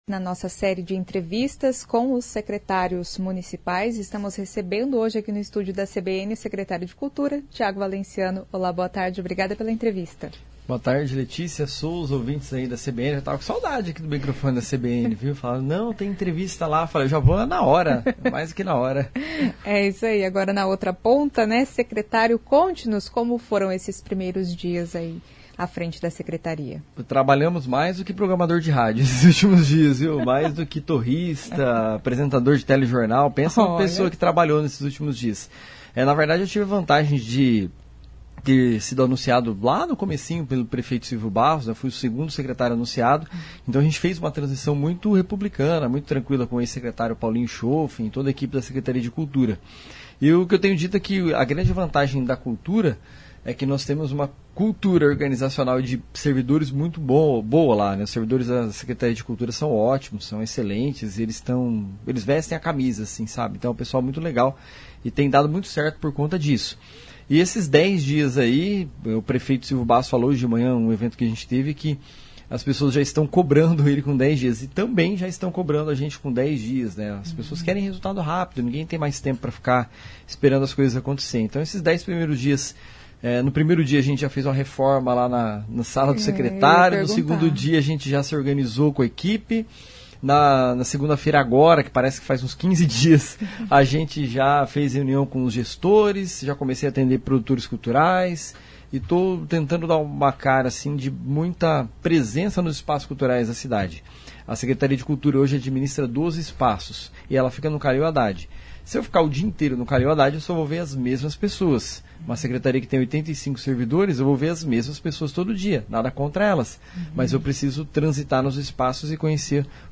Tiago Valenciano, secretário da Cultura, falou do trabalho da pasta na gestão dos 12 espaços culturais municipais.